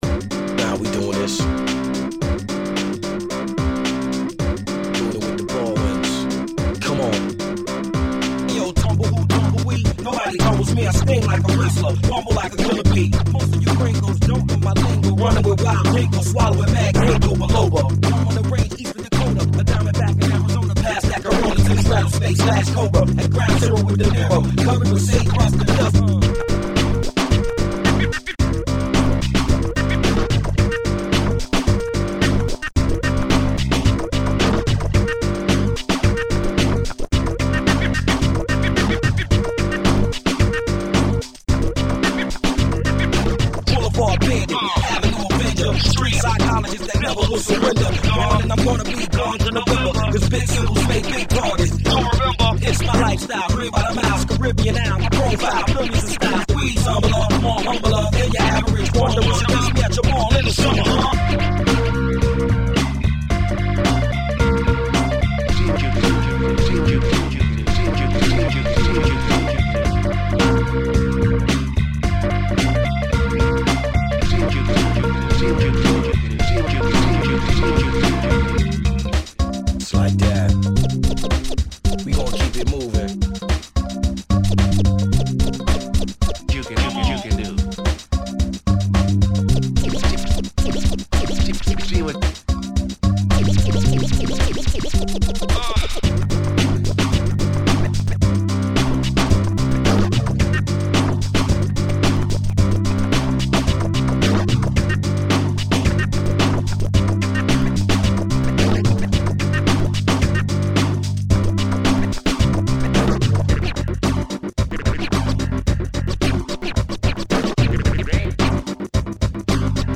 A remix EP